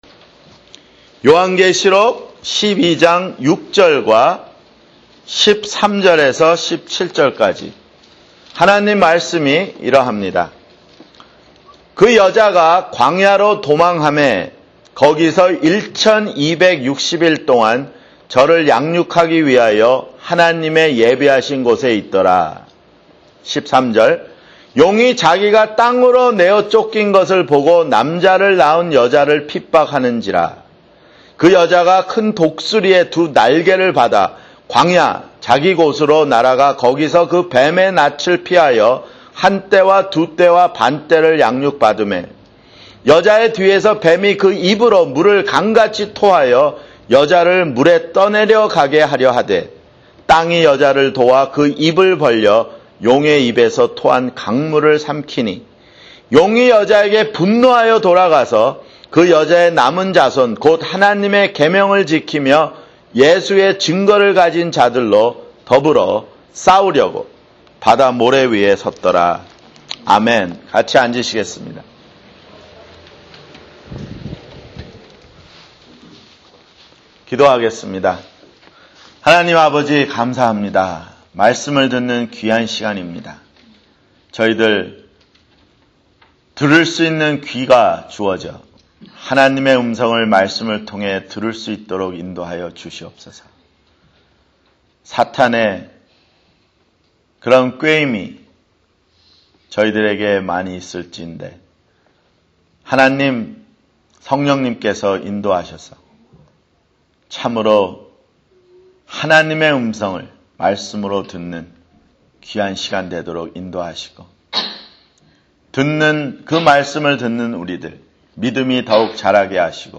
[주일설교] 요한계시록 (45)